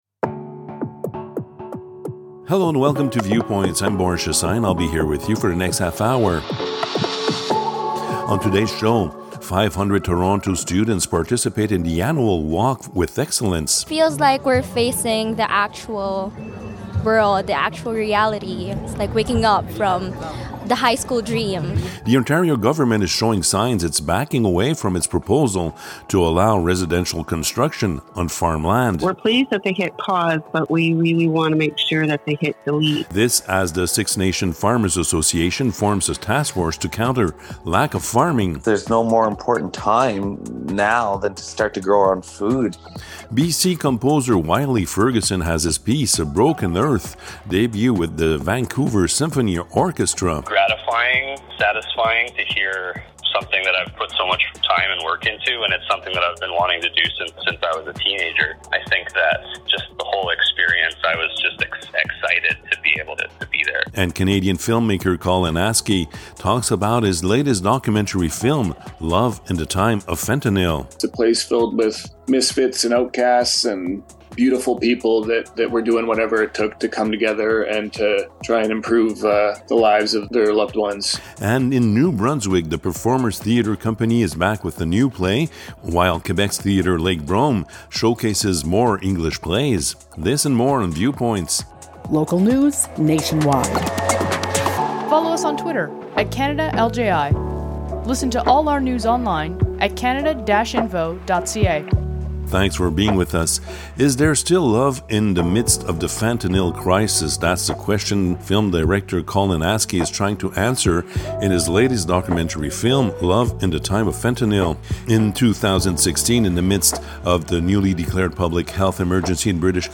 Viewpoints #65: Local news, nationwide Viewpoints #65, CRFC's national radio show.